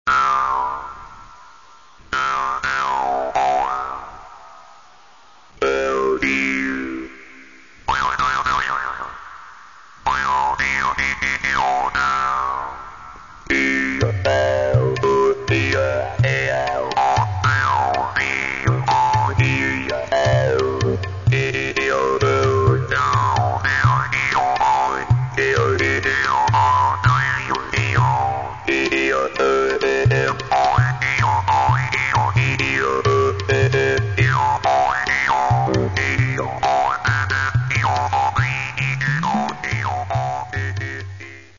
Каталог -> Джаз та навколо -> Інше близьке